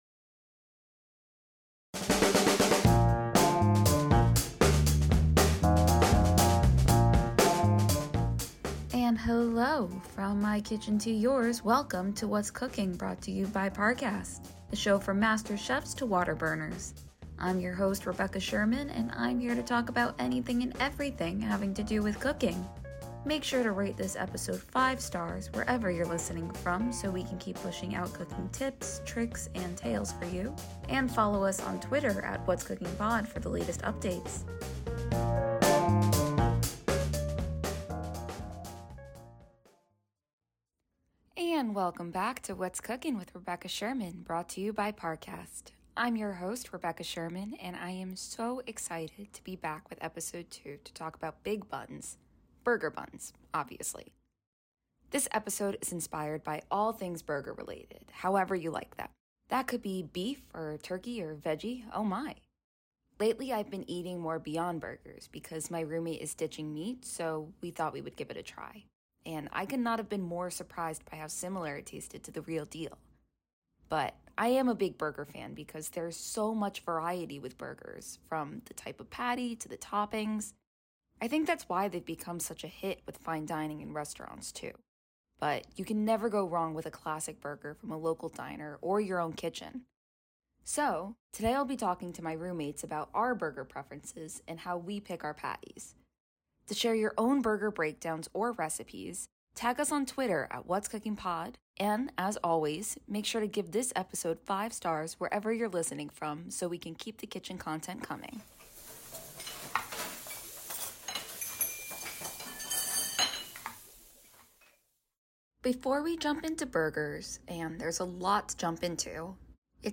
Timestamps 00:00 - show intro with music